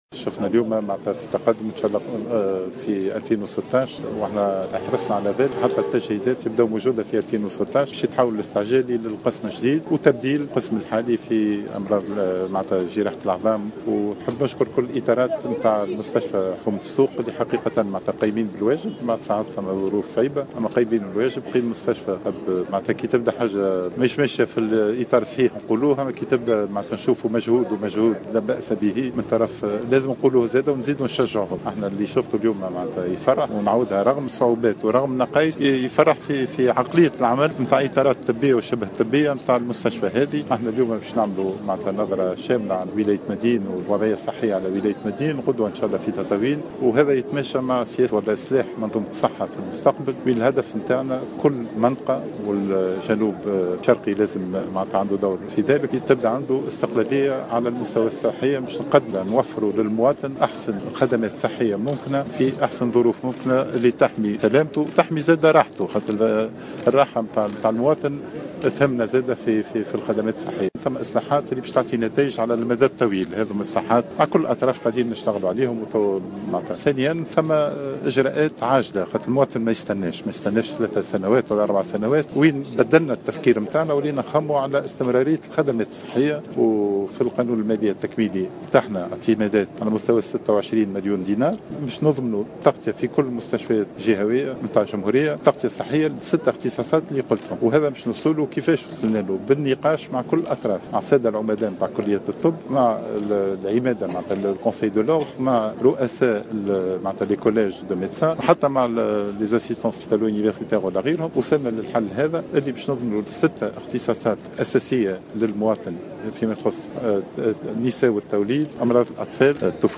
وأوضح العايدي في تصريح